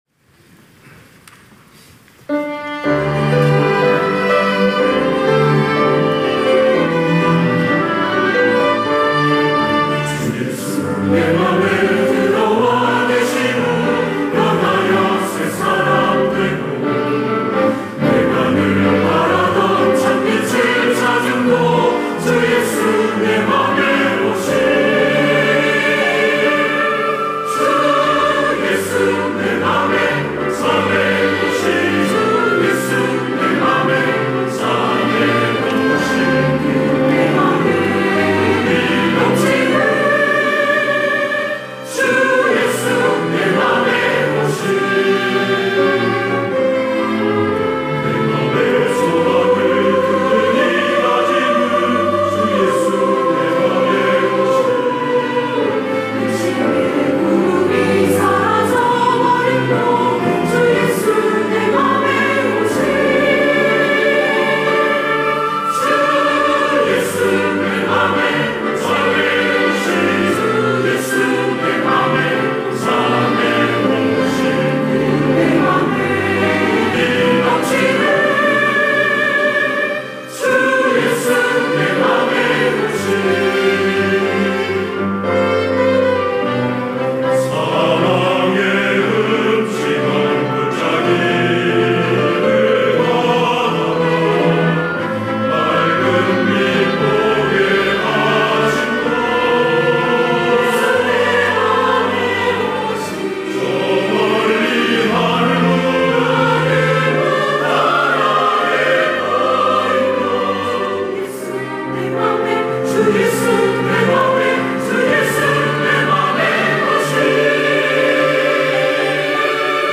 할렐루야(주일2부) - 주 예수 내 맘에 오심
찬양대